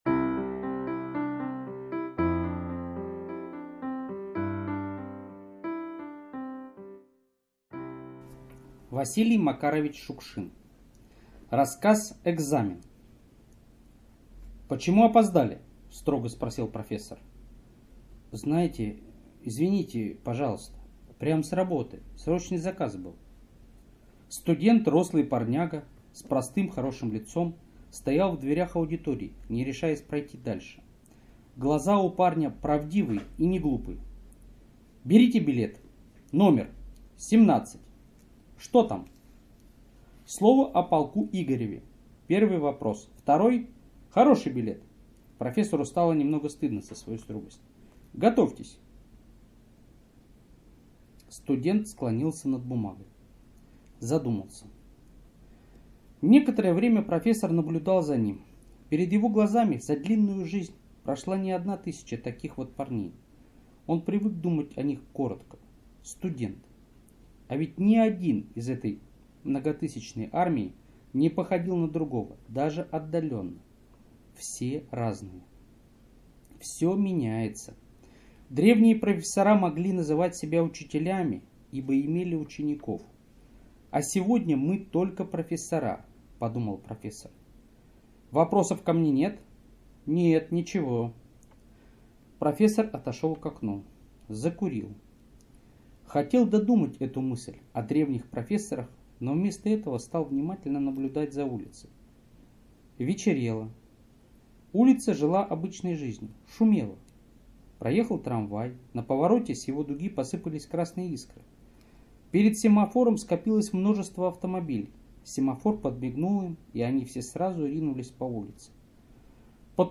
Экзамен - аудио рассказ Шукшина В.М. Рассказ о том, как в послевоенные годы студент заочник сдавал экзамен.